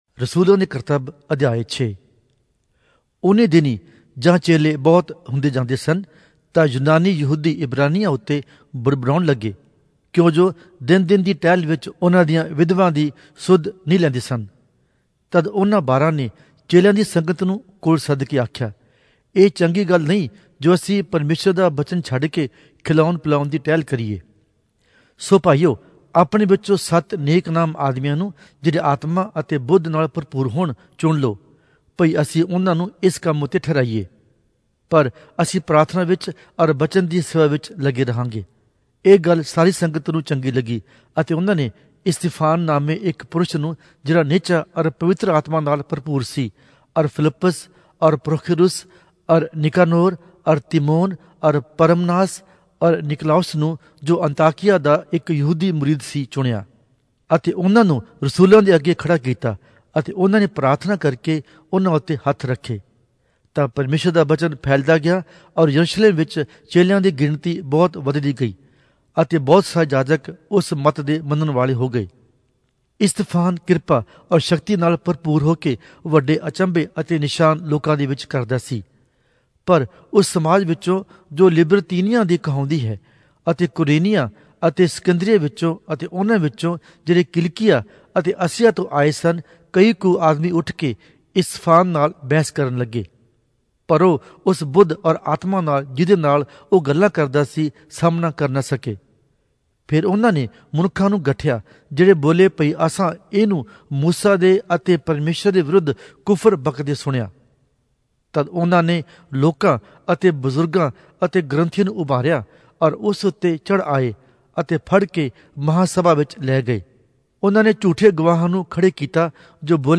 Punjabi Audio Bible - Acts 16 in Orv bible version